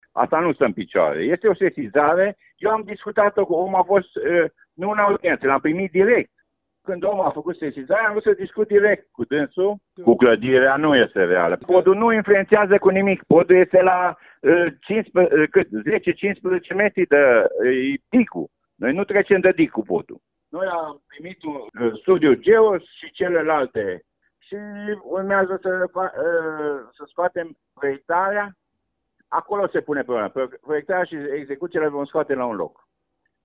Primarul Lugojului, Francisc Boldea, susţine, însă, că temerile nu sunt întremeiate.
insert-boldea.mp3